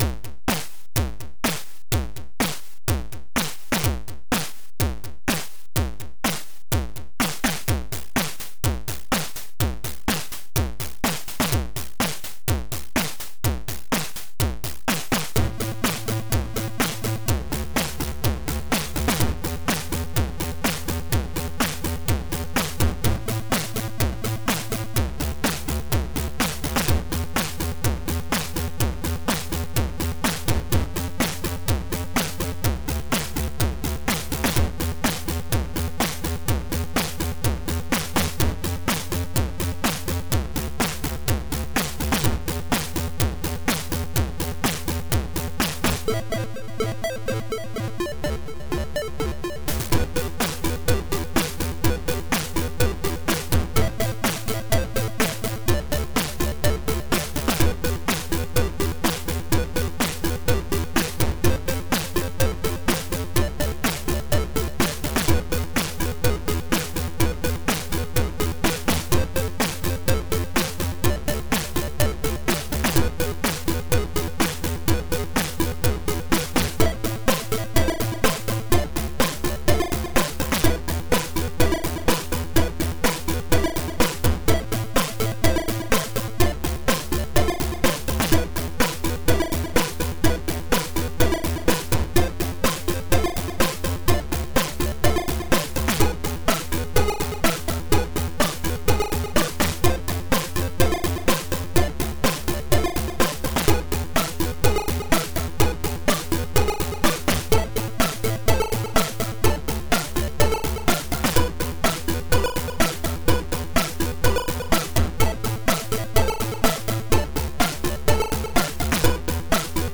• Chip music
• Music is loop-able, but also has an ending